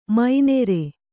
♪ mai nere